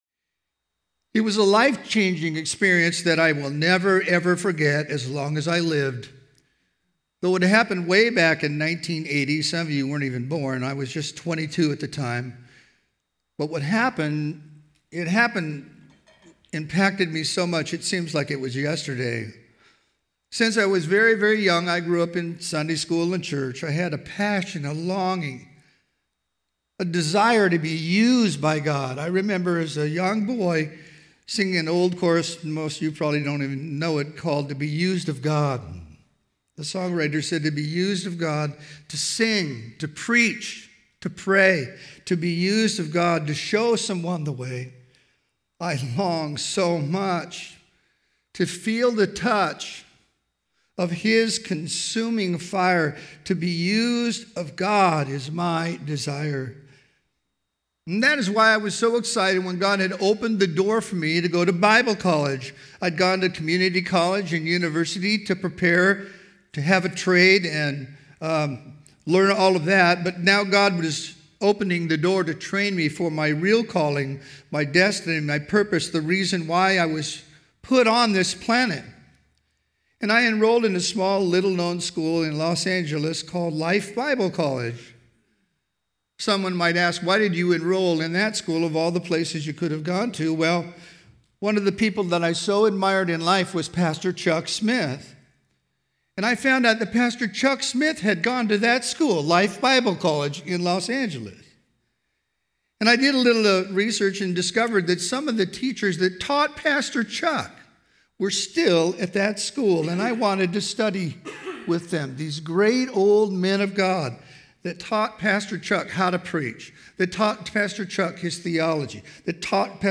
Home » Sermons » A Fresh Vision of God